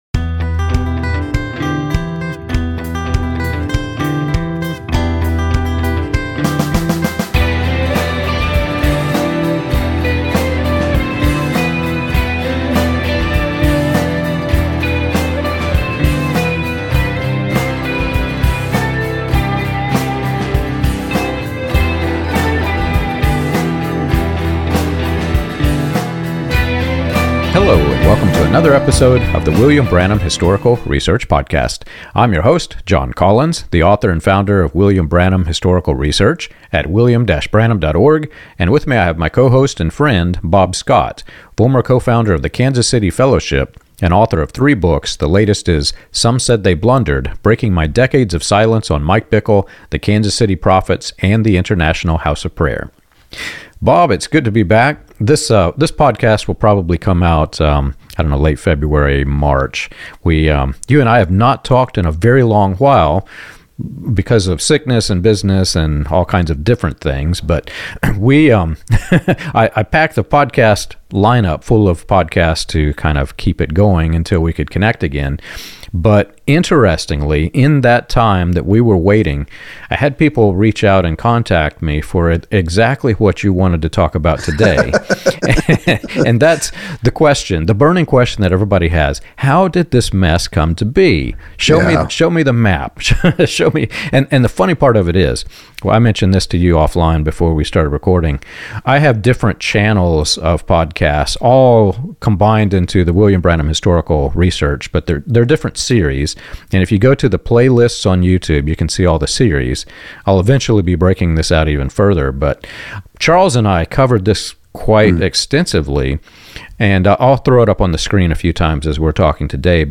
This conversation examines how fascination with secret knowledge, prophetic authority, and end-times certainty often displaced humility, accountability, and love of neighbor.